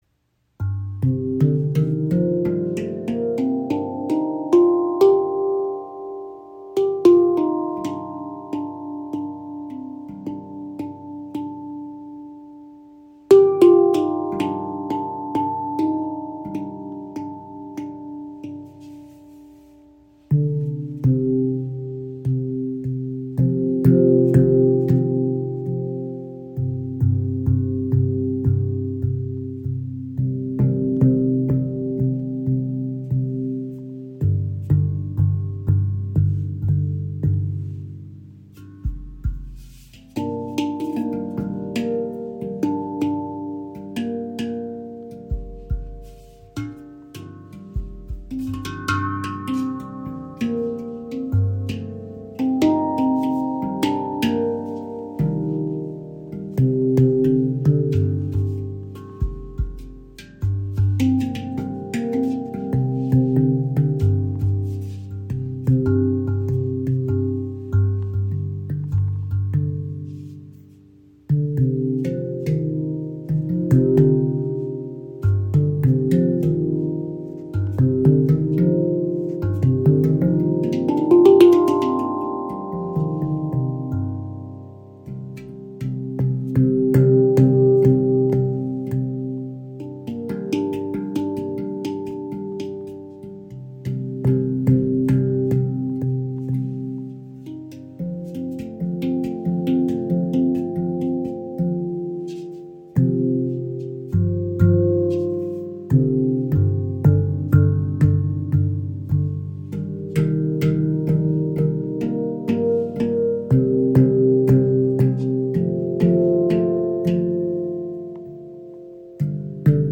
Handpan MAG | G Minor | 13 Klangfelder – tragend & ausgewogen
• Icon Edelstahl-Handpan – warmer, langer Sustain für meditative Musik
Die G Minor Stimmung entfaltet eine tiefe, ruhige Klanglandschaft mit warmer Erdung und sanfter Weite. Der tiefe Grundton trägt den Klang, während die darüberliegenden Töne melodische Bewegung und Ausdruck eröffnen.
So entsteht ein Klangbild, das meditativ, leicht mystisch und zugleich offen für emotionale Ausdruckskraft wirkt.
Töne: G – (C D D# F) G A A# C D D# F G